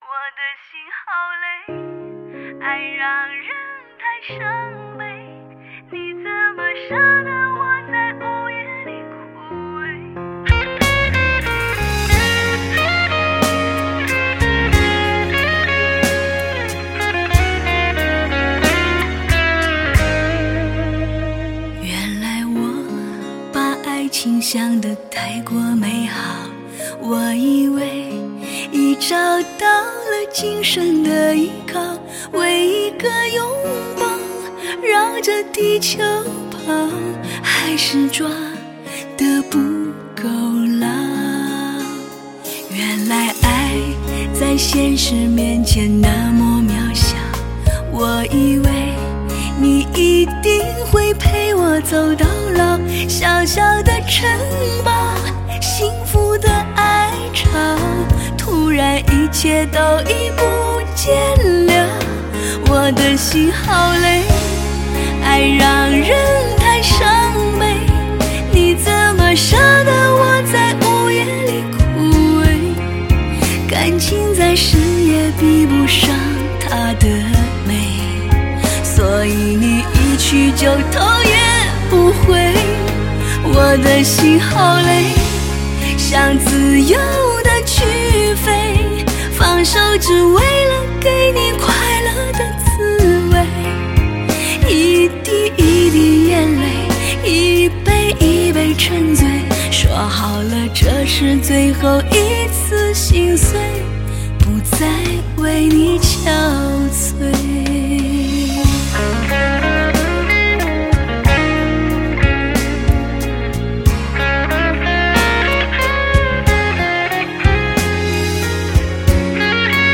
爱的勇气与智慧，在赋有磁性甜美声音的演绎下越发完美。